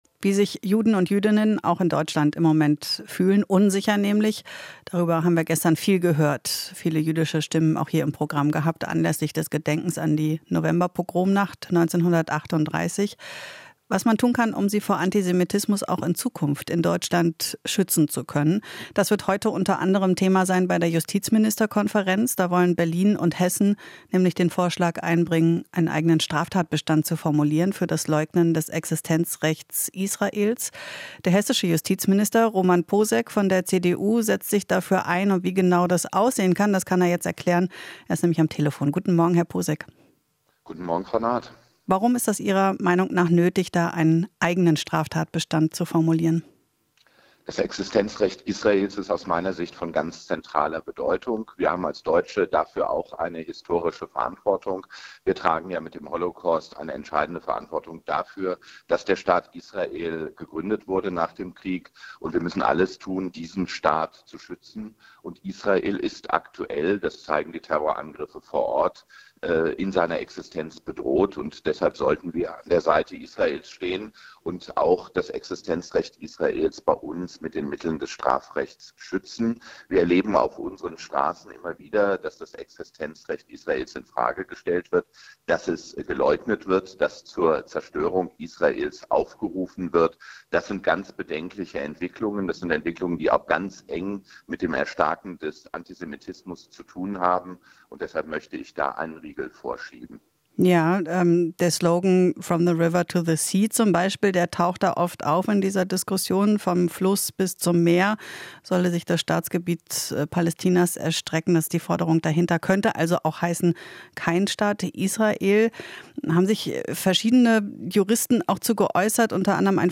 Interview - Minister Poseck: Existenzrecht Israels darf nicht in Frage gestellt werden